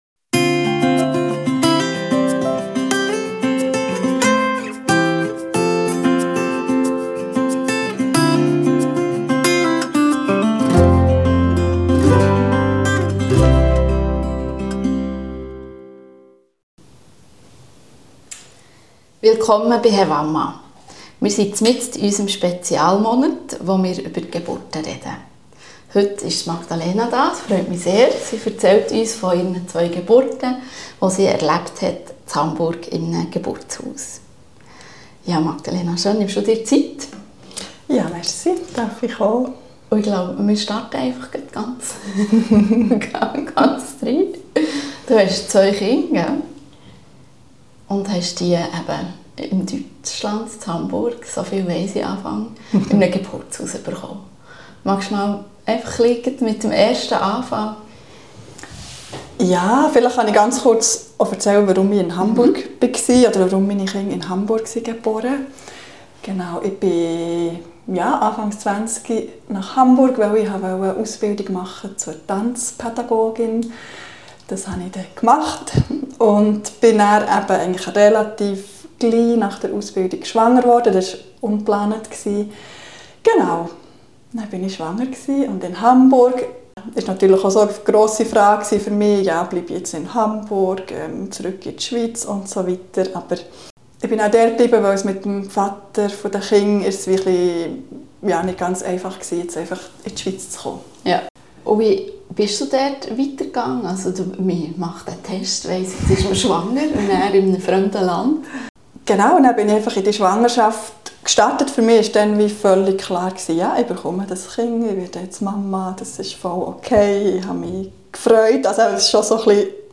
Ein inspirierendes Gespräch über Geburtsvorbereitung, das Vertrauen in den eigenen Körper und die Rolle der Hebammen in der Unterstützung von Gebärenden.